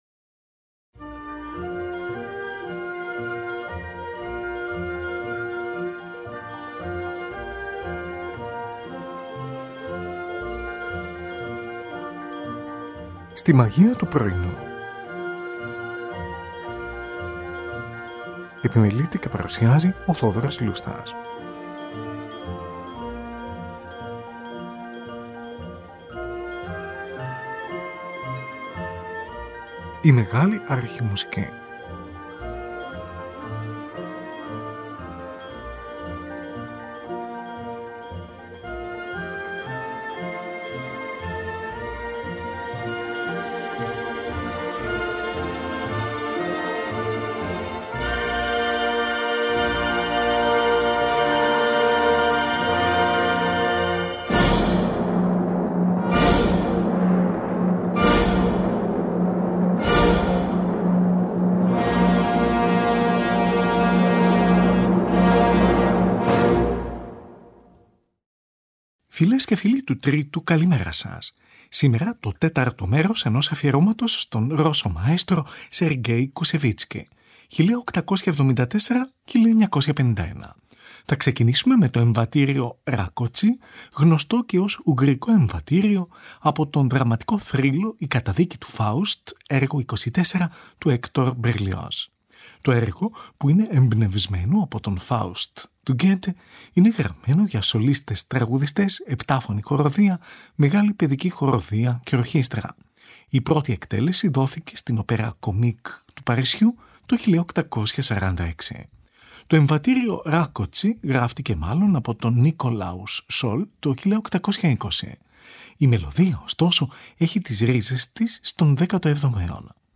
συμφωνία σε τέσσερα μέρη, με βιόλα obbligato
Σόλο βιόλα